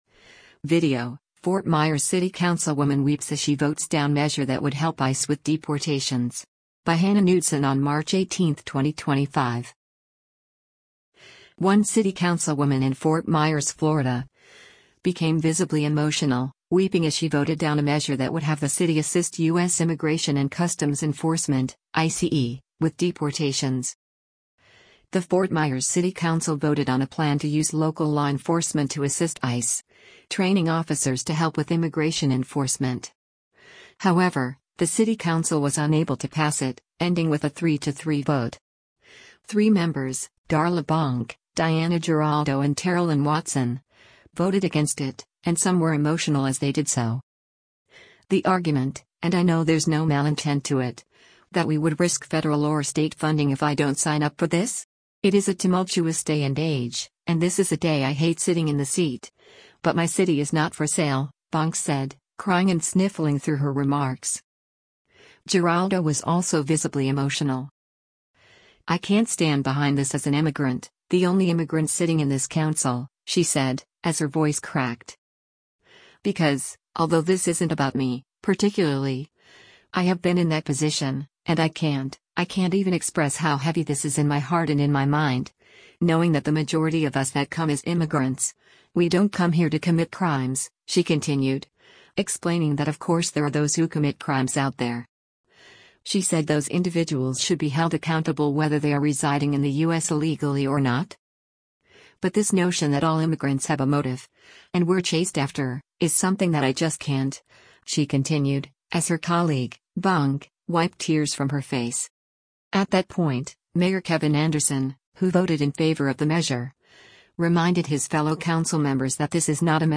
VIDEO: Fort Myers City Councilwoman Weeps as She Votes Down Measure That Would Help ICE with Deportations
Fort Myers City Council
One city councilwoman in Fort Myers, Florida, became visibly emotional, weeping as she voted down a measure that would have the city assist U.S. Immigration and Customs Enforcement (ICE) with deportations.
“I can’t stand behind this as an immigrant, the only immigrant sitting in this council,” she said, as her voice cracked.